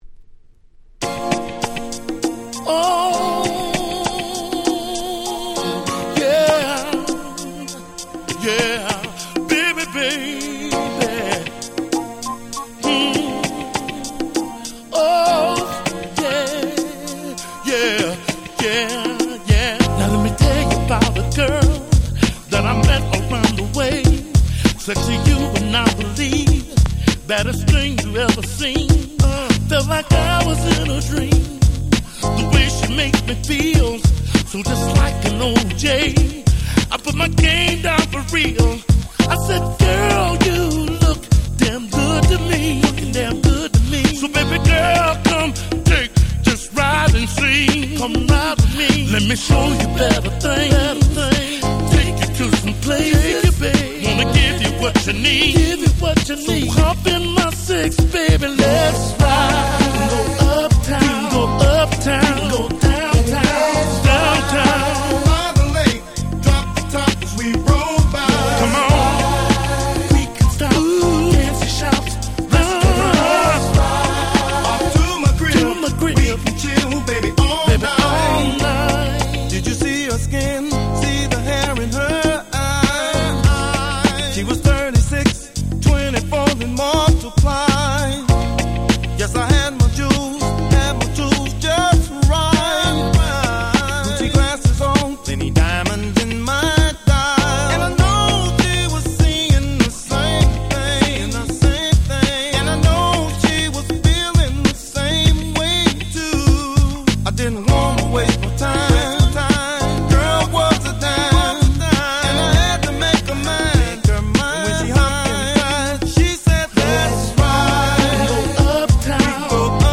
01' Very Nice R&B !!
大人の魅力プンプンのイケてるMidダンサー！